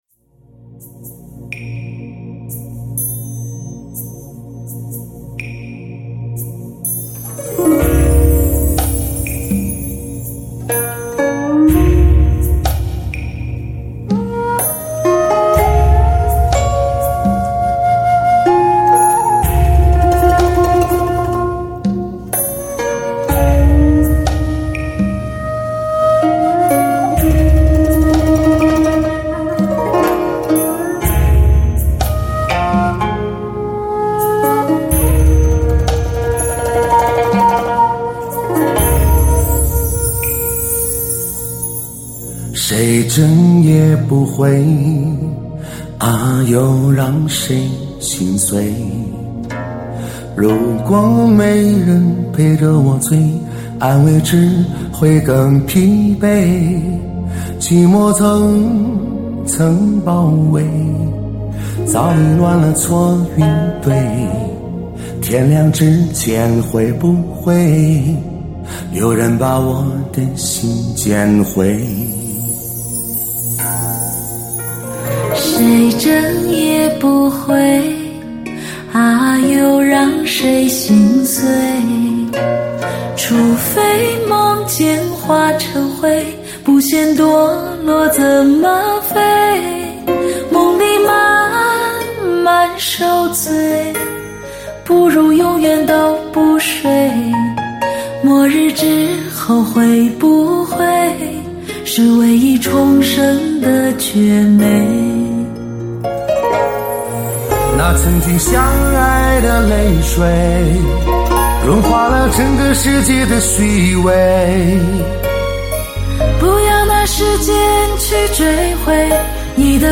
专辑格式：DTS-CD-5.1声道
一辑不容错失的声色魅力天碟，炙手可热的流行潮曲，倍具声色感染力的至爱靓声。